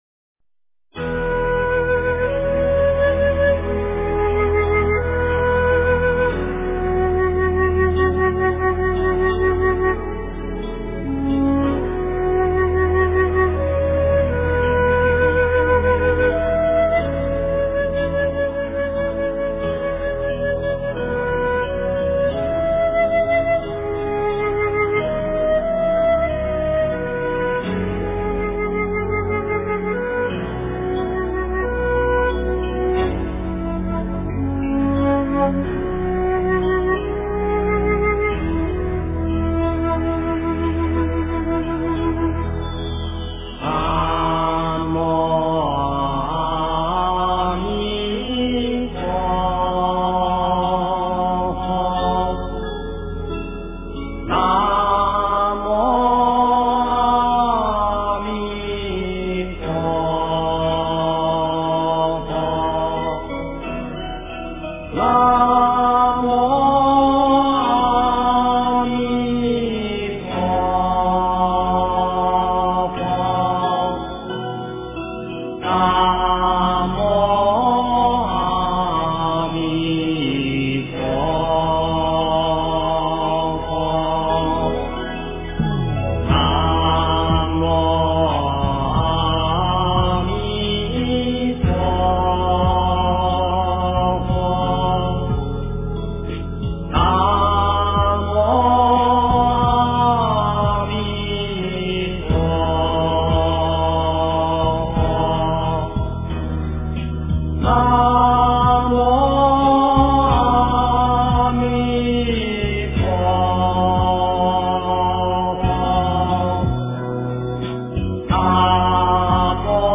真言
佛音 真言 佛教音乐 返回列表 上一篇： 地藏王菩萨超度心咒--十八一心童声合唱团 下一篇： 观音心咒--念佛静心曲 相关文章 六字大明咒-梵唱--圆满自在组 六字大明咒-梵唱--圆满自在组...